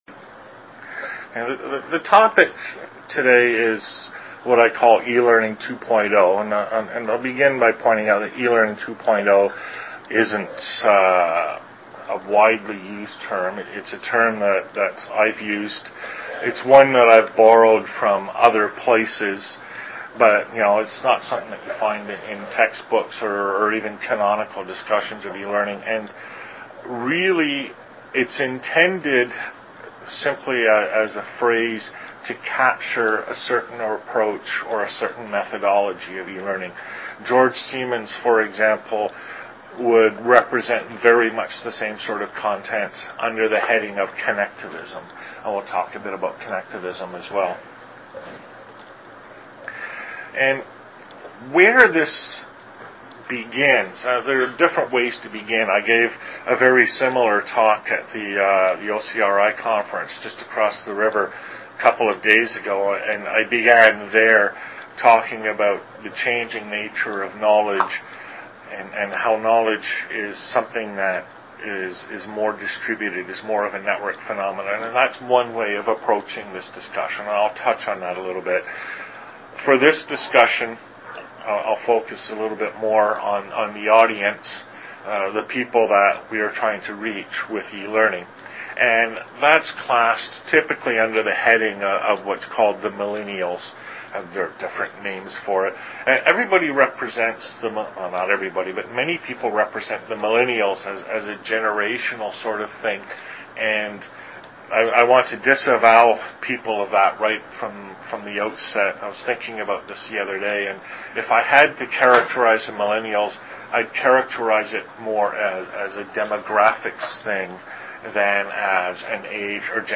I spent the day at the Canadian Heritage Information Network engaged in a lengthy and free-wheeling discussion of the concepts surrounding E-Learning 2.0 Although the PowerPoint Slides for my presentation today are basically the same as those I used in Edmonton a few months ago, the discussion was much more wide ranging.